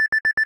Techmino/media/effect/chiptune/warn_beep.ogg at a4c775174be6437d1aa5d2c8ea8ee39ef2da5ae5
warn_beep.ogg